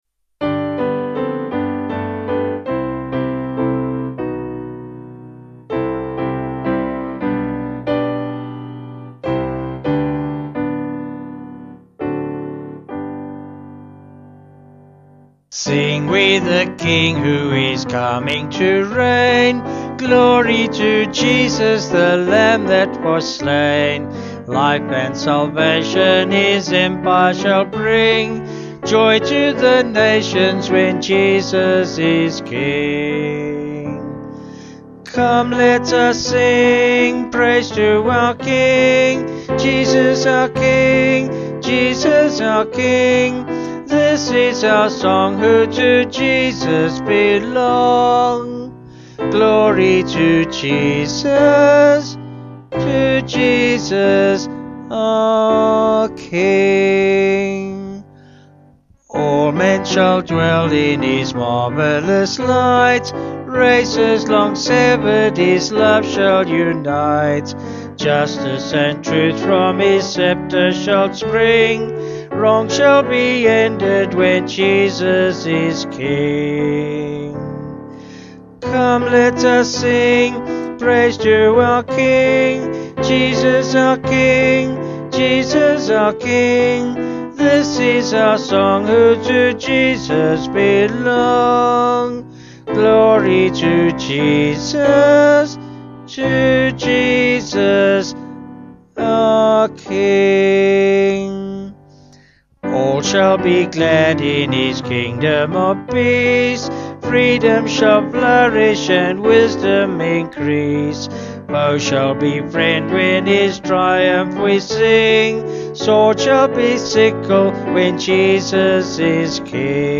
Vocals and Piano   257.2kb Sung Lyrics